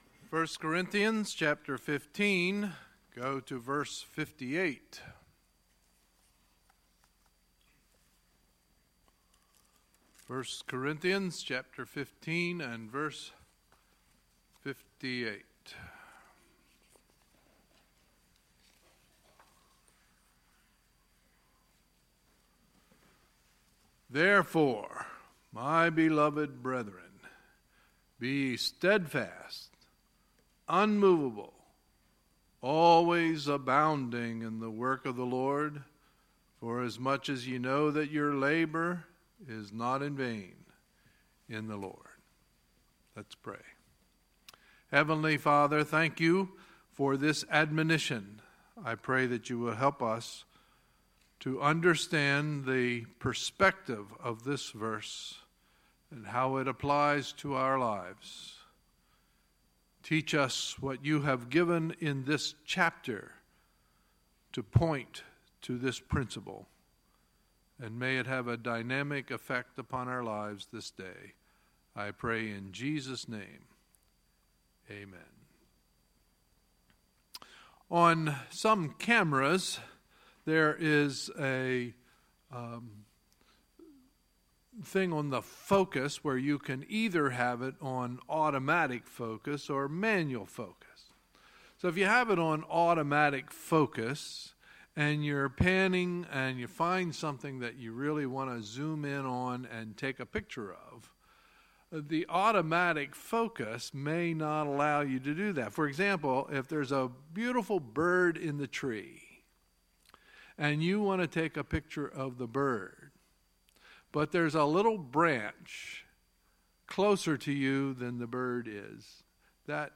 Sunday, July 16, 2017 – Sunday Morning Service